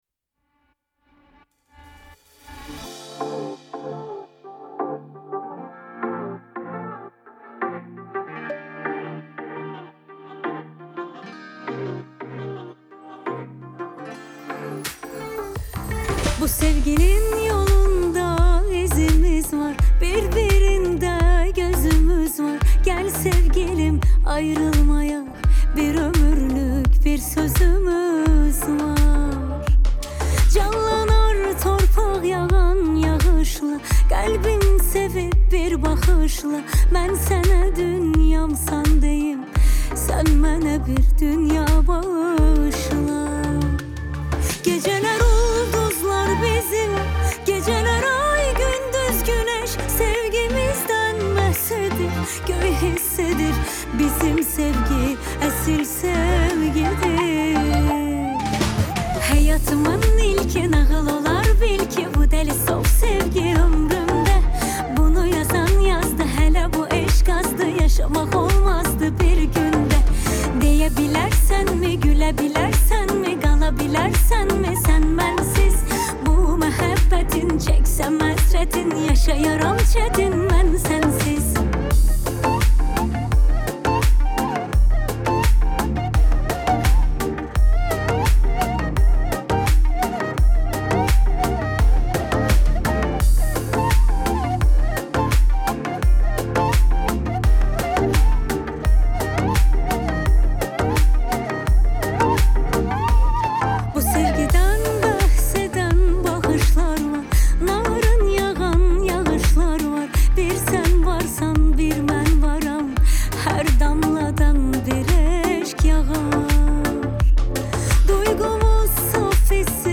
آهنگ آذربایجانی آهنگ غمگین آذربایجانی آهنگ هیت آذربایجانی